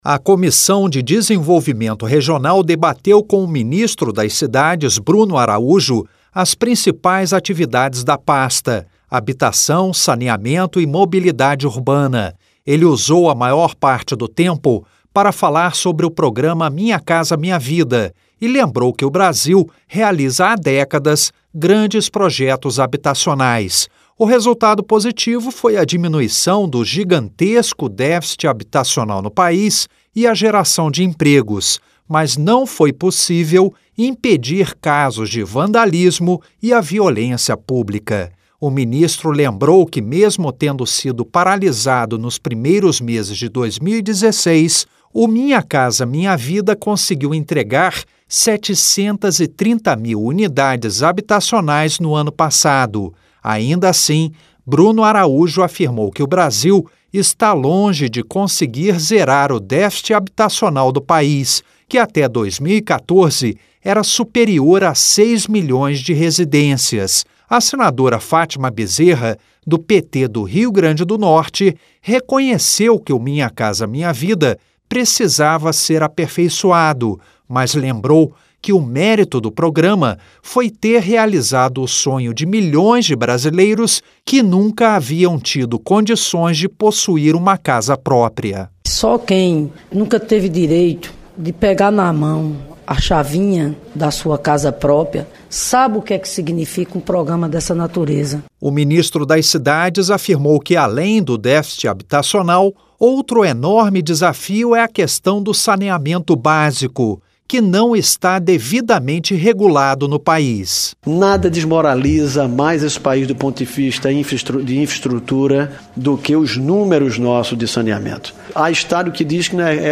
LOC: BRUNO ARAÚJO PARTICIPOU DE AUDIÊNCIA PÚBLICA NESTA QUARTA-FEIRA E ANUNCIOU QUE O GOVERNO VAI MANDAR UMA MP SOBRE AS RELAÇÕES JURÍDICAS E AS RESPONSABILIDADES NO SETOR DE SANEAMENTO.
Rádio Senado